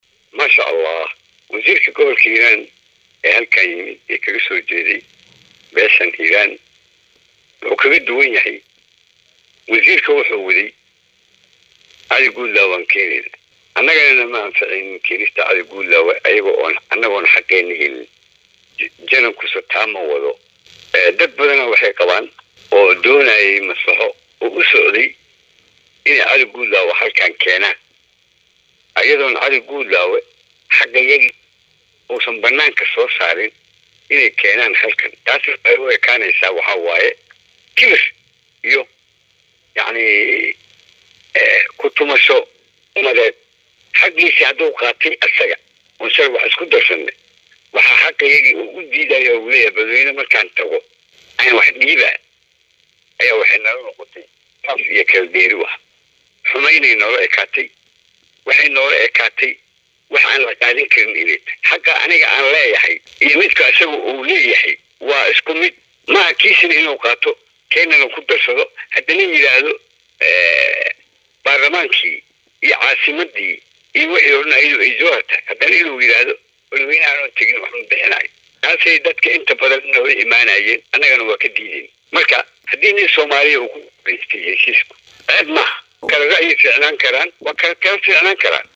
DHAGEYSO:Janeraal Xuud oo wareysi uu bixiyay ku sheegay in uu Muqdisho tagaya